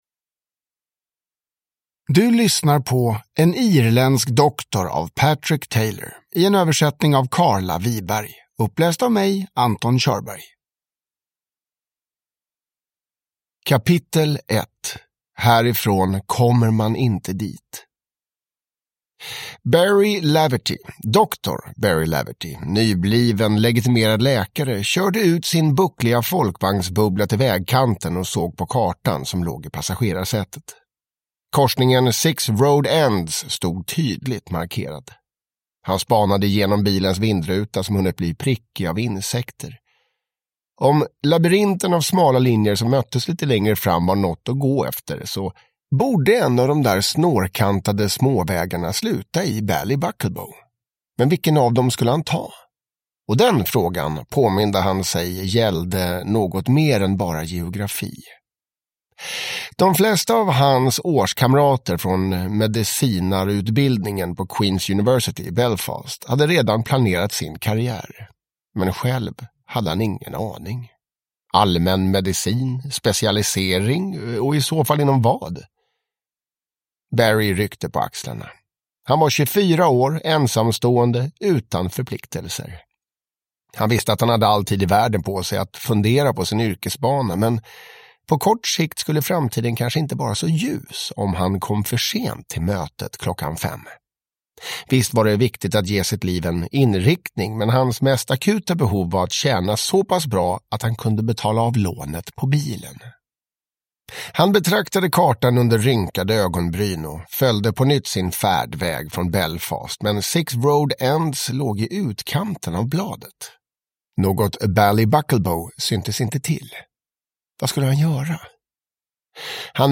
En irländsk doktor (ljudbok) av Patrick Taylor